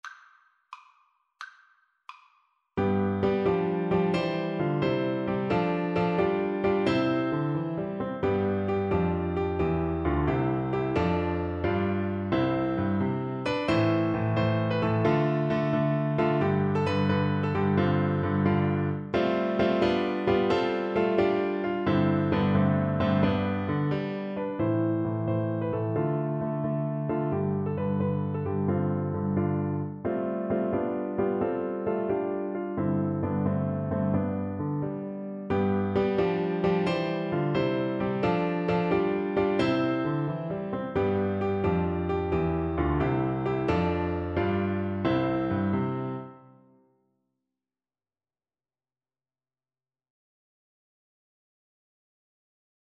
French Horn
C major (Sounding Pitch) G major (French Horn in F) (View more C major Music for French Horn )
E4-E5
6/8 (View more 6/8 Music)
Traditional (View more Traditional French Horn Music)